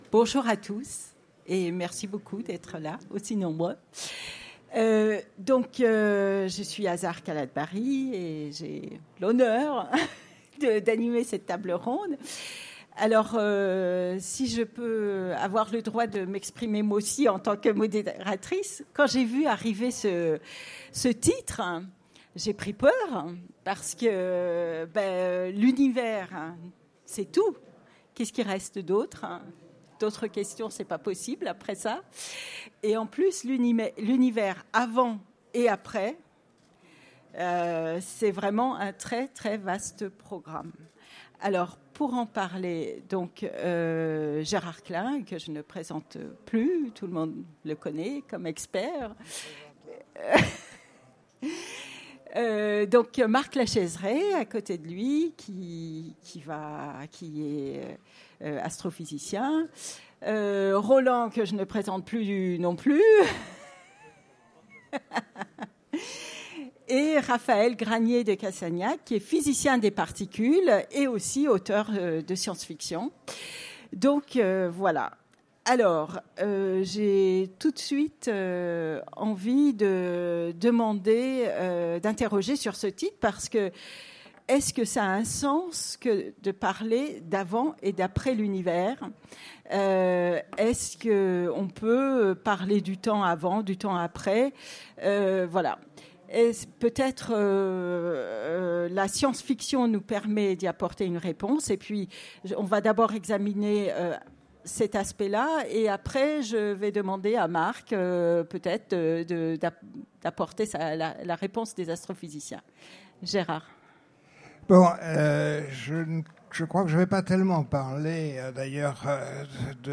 Utopiales 2017 : Conférence L’univers et après, ou avant ?